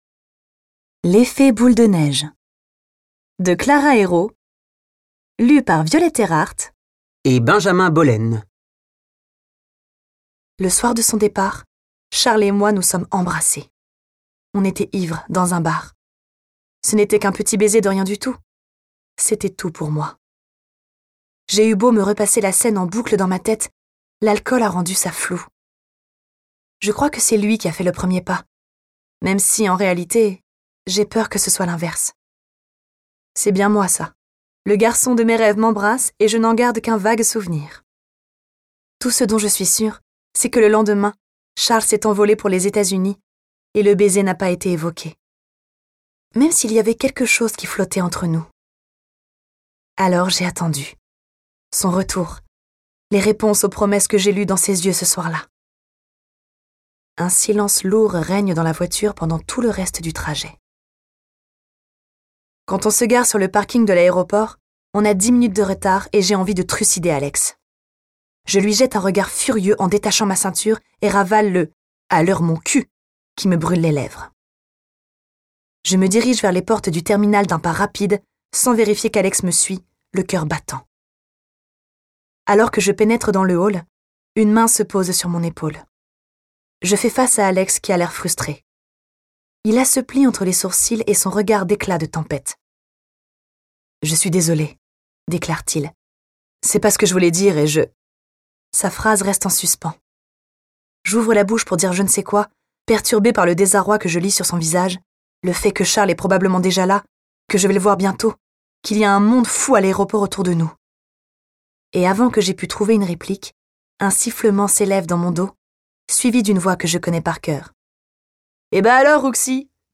Interprétation humaine